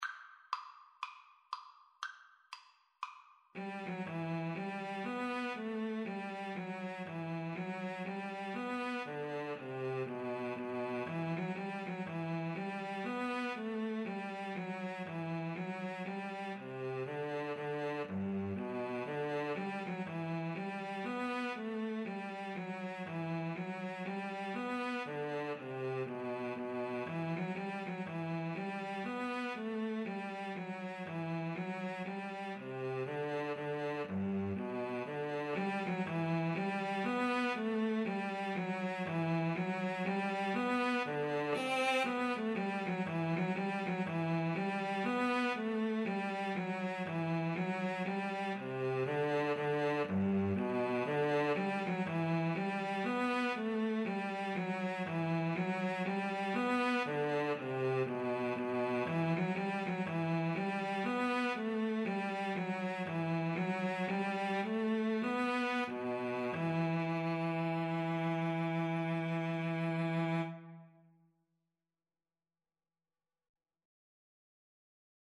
Quick Swing = c. 120
4/4 (View more 4/4 Music)
Jazz (View more Jazz Flute-Cello Duet Music)
Rock and pop (View more Rock and pop Flute-Cello Duet Music)